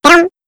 yoshi tongue.wav